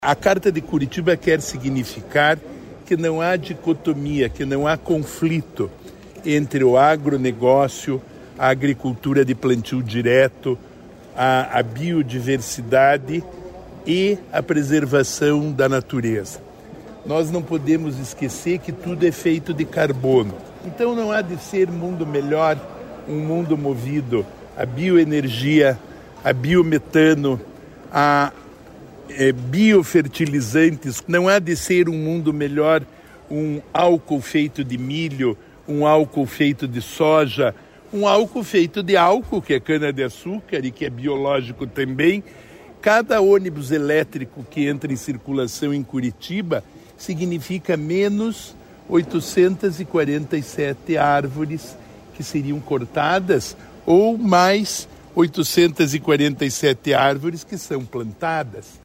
Sonora do secretário Estadual do Desenvolvimento Sustentável, Rafael Greca, sobre a Carta de Curitiba